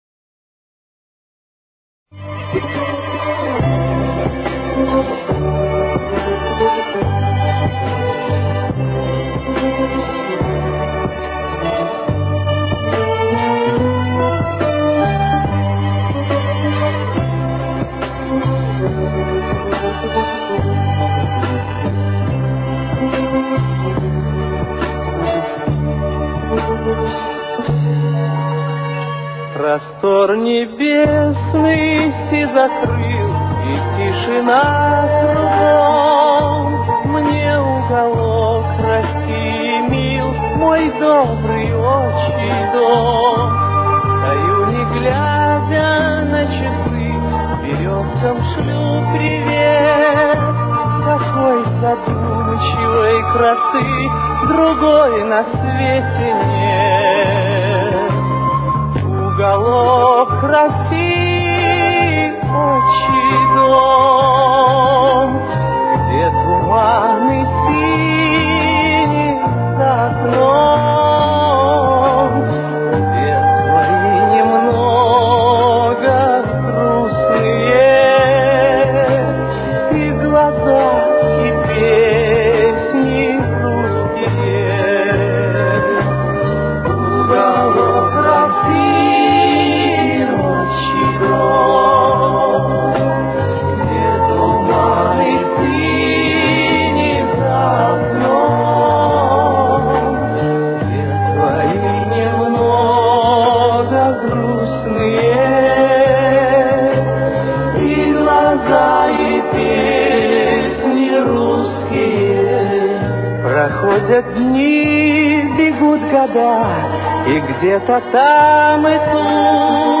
Тональность: Соль минор. Темп: 70.